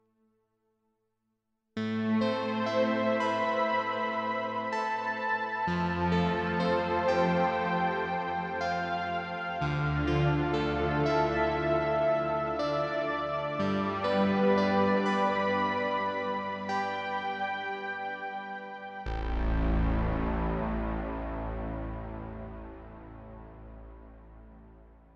Крутил еще тут Boss RV-500. Этакую смесь бигскайского Bloоm и Cloud накрутил. Вложения BossSV500-sfxslowverblargehall.mp3 BossSV500-sfxslowverblargehall.mp3 393,6 KB · Просмотры: 822 Последнее редактирование: 25 Июн 2021